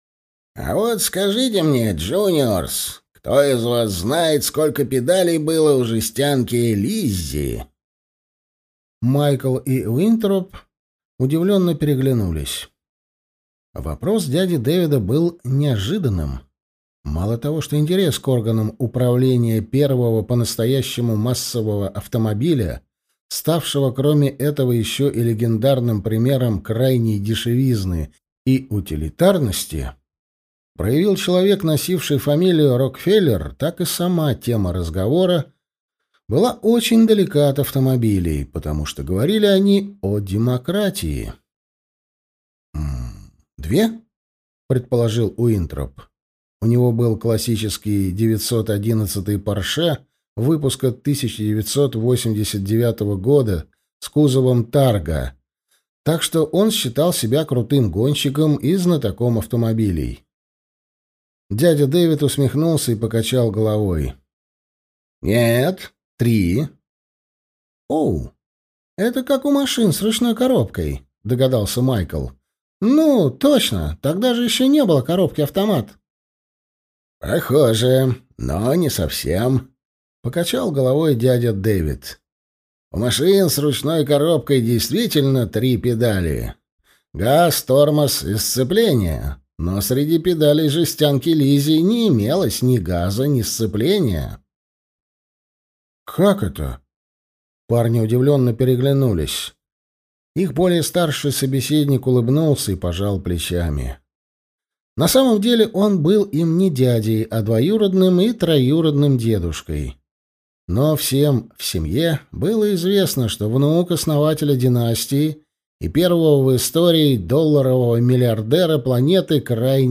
Аудиокнига Настоящее прошлое. Однажды в Америке | Библиотека аудиокниг